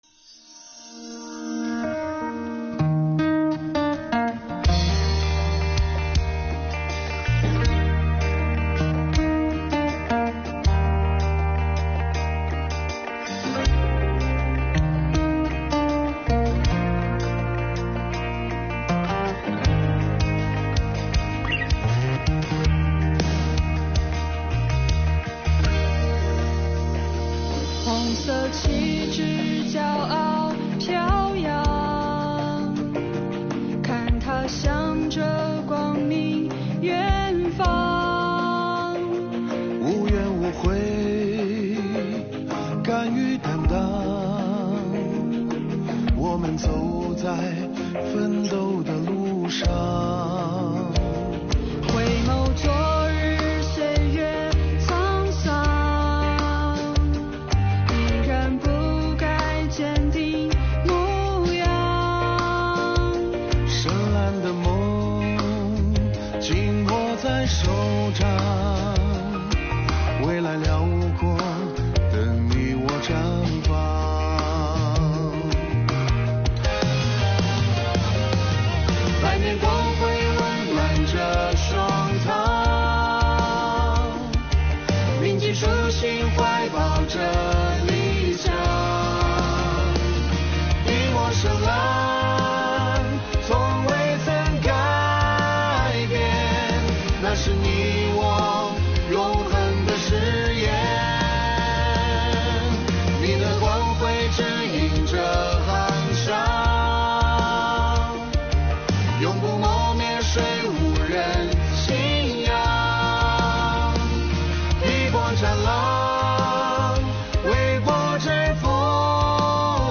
为庆祝中国共产党成立100周年，新疆税务干部创作并深情演唱建党百年主题歌曲《光辉》，以昂扬向上的精神状态唱响税务人跟党走、守初心、担使命的时代主旋律，以歌声向党献礼。